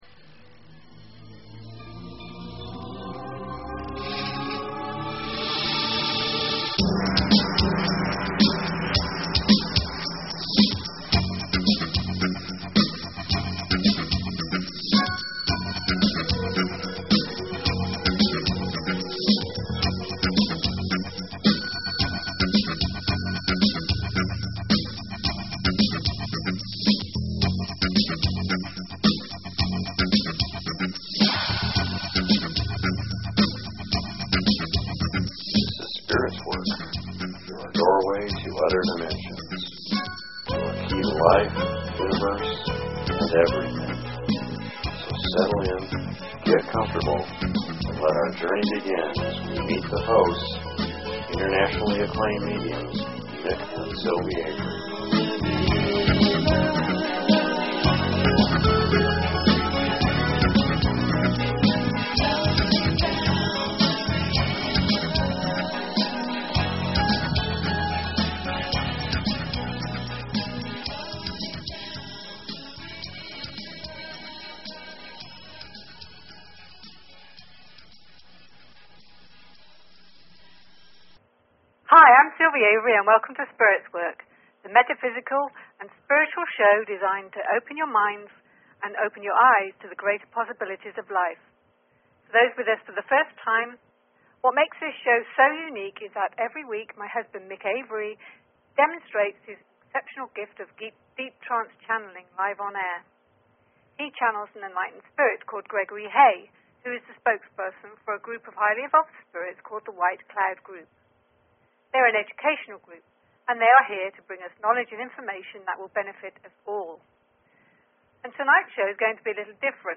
Talk Show Episode, Audio Podcast, Spirits_Work and Courtesy of BBS Radio on , show guests , about , categorized as
Show Headline Spirits_Work Show Sub Headline Courtesy of BBS Radio AKASHIC READINGS LIVE: 3 regular listeners signed up for an on-air Akashic Spirits Work Please consider subscribing to this talk show.